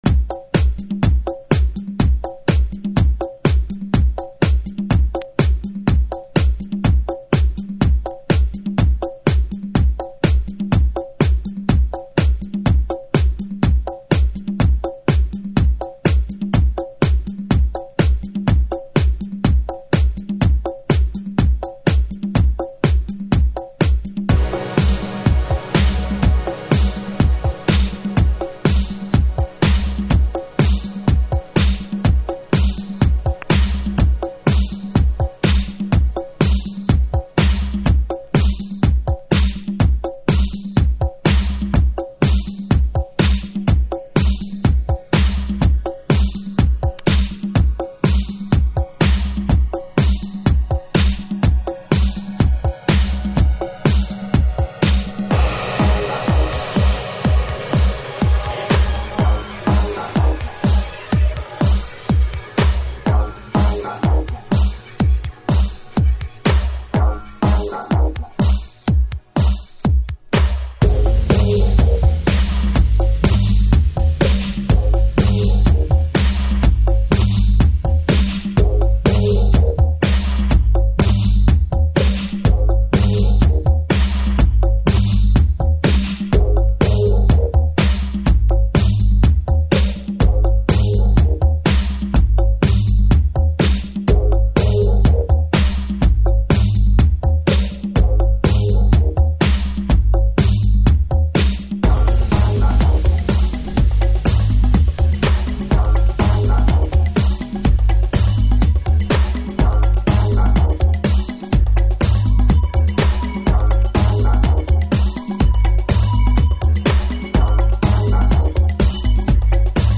House / Techno
パワフルなトラックメイクですね。